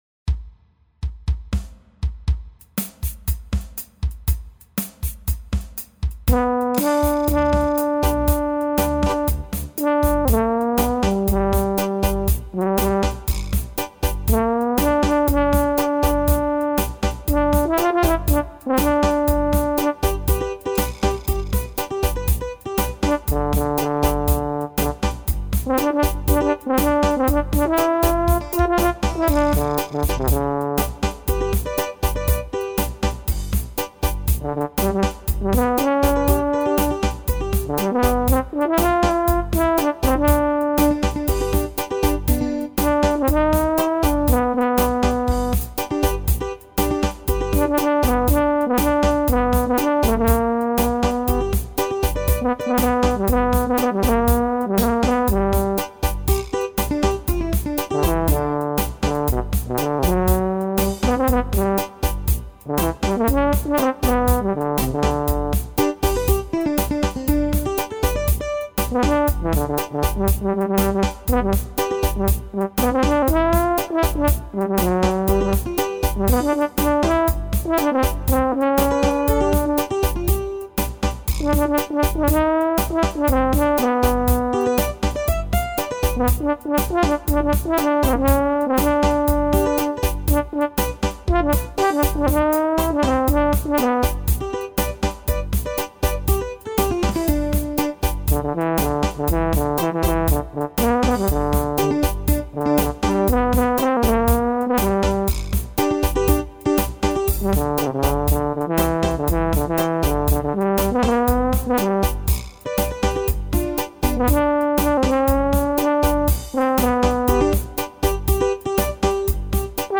Jamaican rasta tune